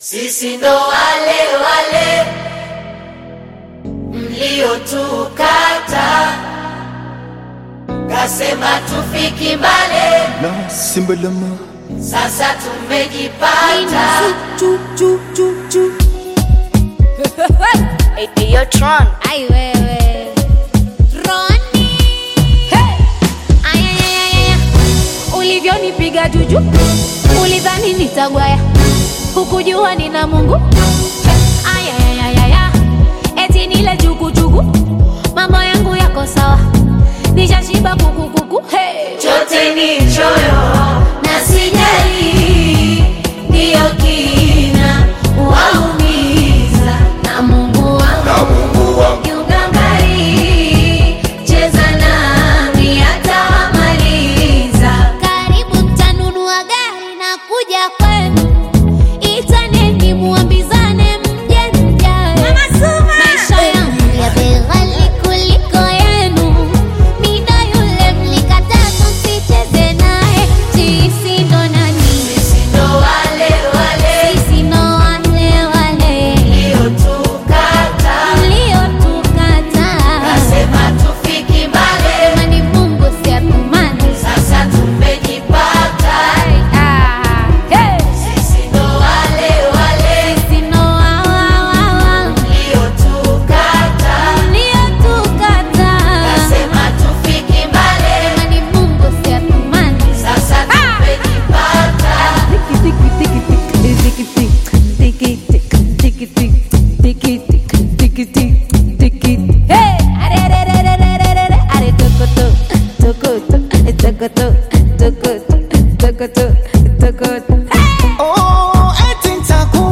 Talented Tanzanian Singer